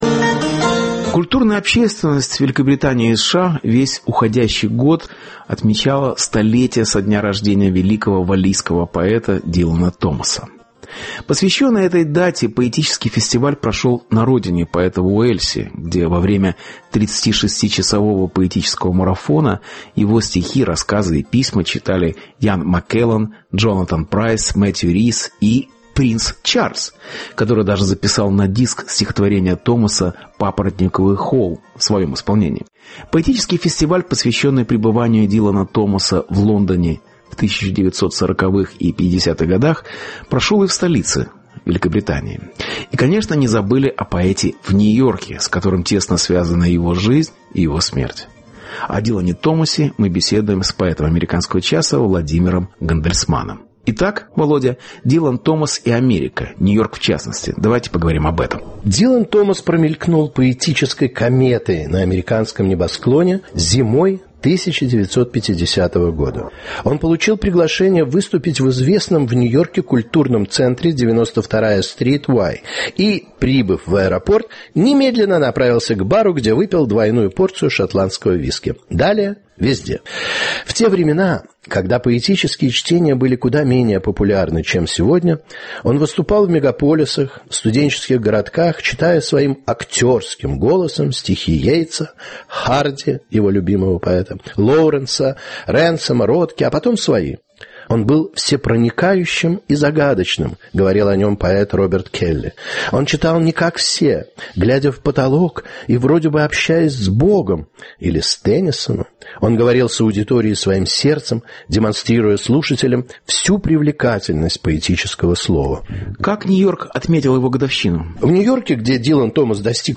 Столетие Дилана Томаса в Нью-Йорке Беседа